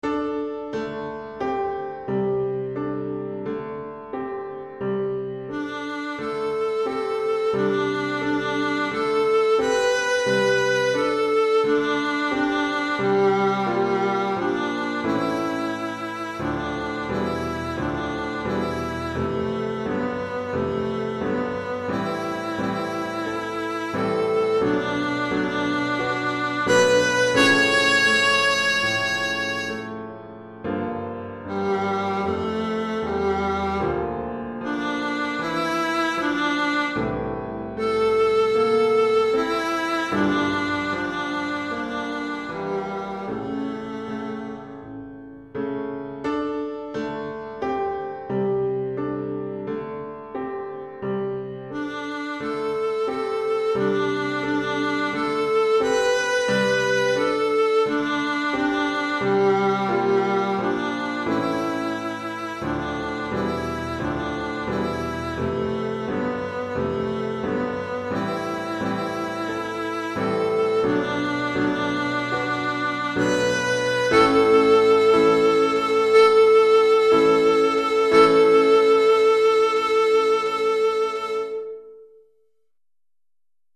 pour alto et piano DEGRE CYCLE 1